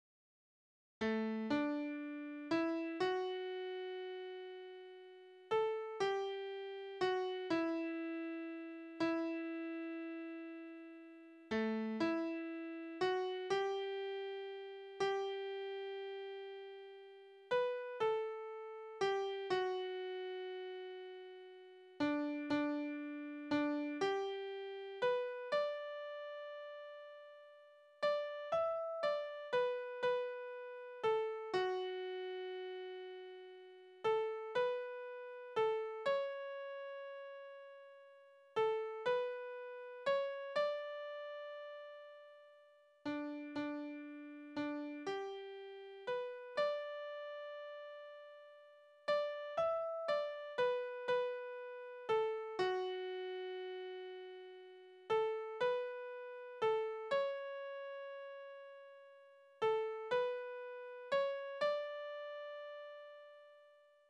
« O-10660 » Es rauscht der Wald so schön Naturlieder: Es rauscht der Wald so schön, es rauscht der Wald so schön wenn leis die Abendwinde wehn. Tonart: D-Dur Taktart: 3/4 Tonumfang: Oktave, Quinte Besetzung: vokal Externe Links